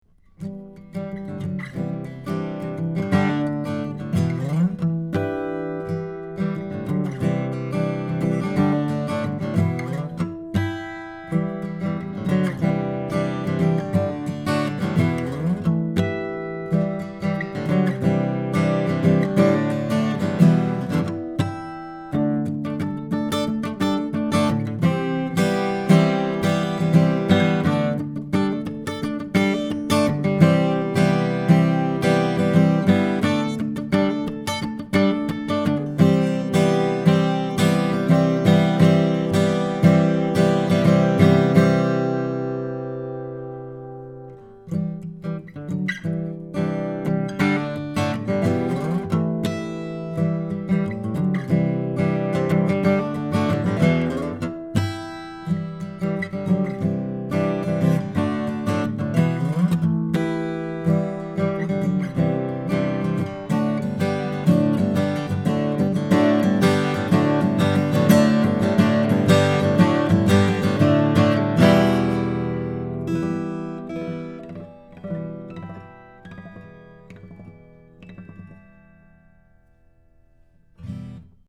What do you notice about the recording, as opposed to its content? Here are 2 dozen quick, 1-take MP3s using this U87 in a large room -- running on battery power -- into a Sony PCM D1 flash recorder (which does not have P48 Phantom Power), with MP3s made from Logic. These tracks are just straight signal with no additional EQ, compresson or effects: Omni: